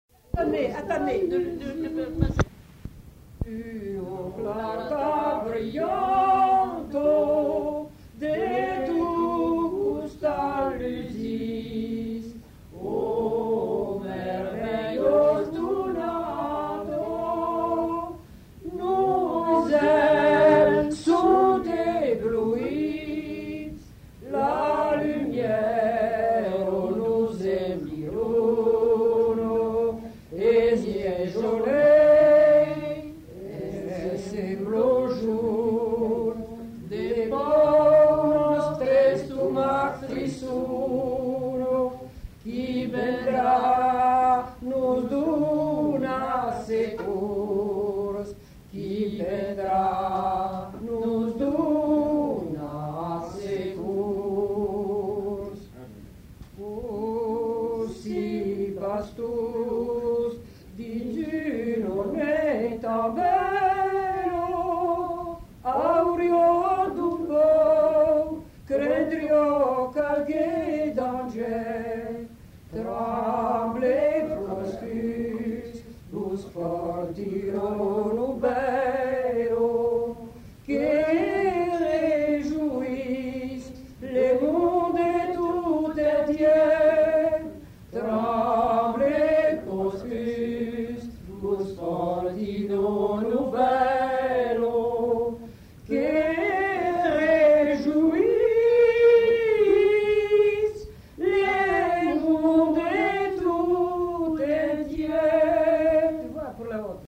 Lieu : Prat-Communal (lieu-dit)
Genre : chant
Type de voix : voix de femme ; voix d'homme
Production du son : chanté
Classification : noël